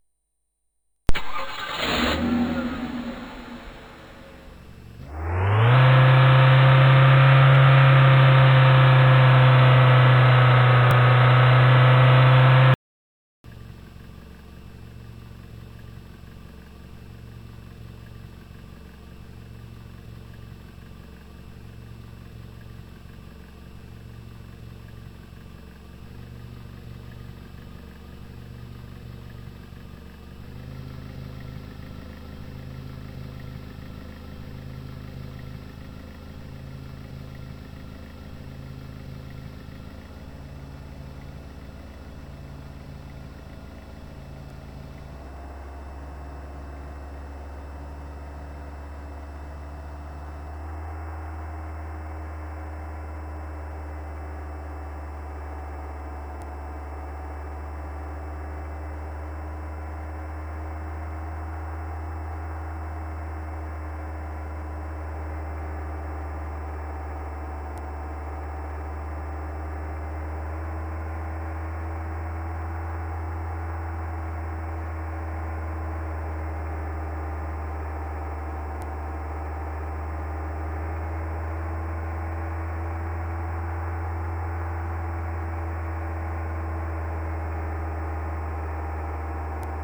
汽车引擎音效
fadongji.mp3